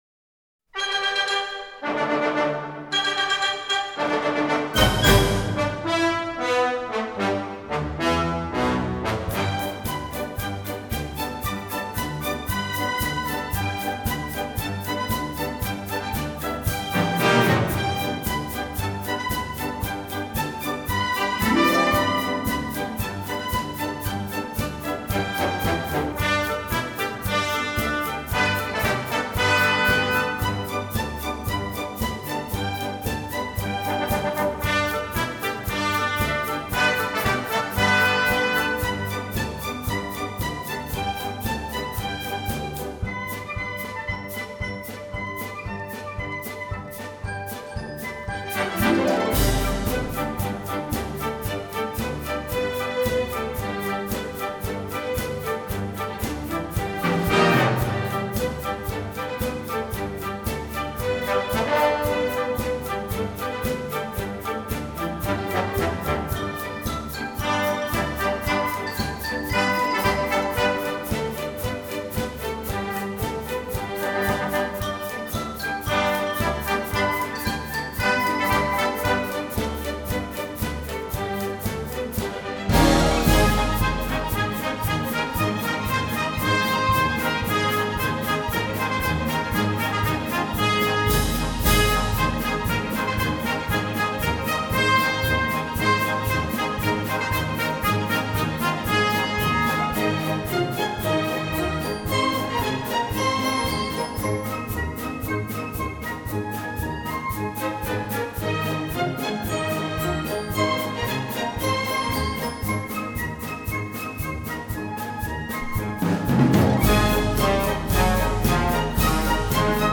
是一張以精緻古典管弦樂、室內樂、及聲樂表現台灣歌謠全新生命力的唱片